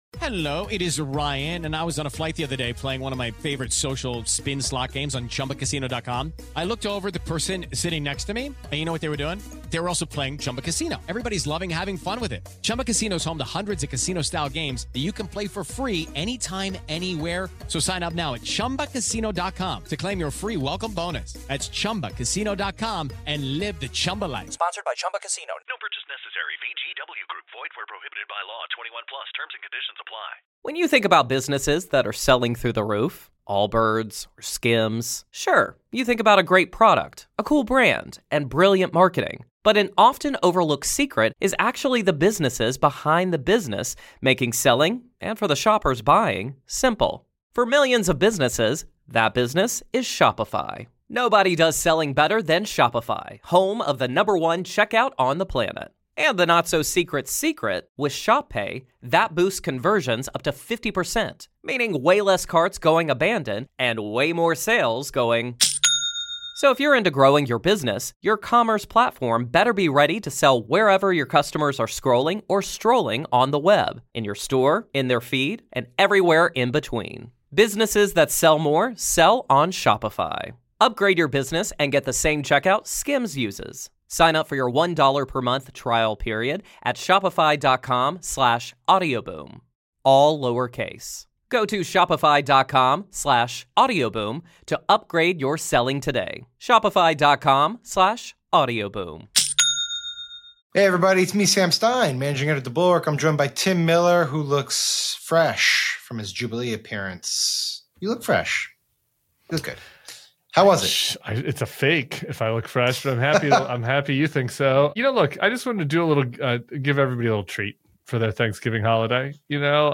NOTE: This is a special preview of Tim and Sam's members-only Q&A. To listen to the whole thing, become a paid Bulwark member on Apple Podcasts, Substack or YouTube!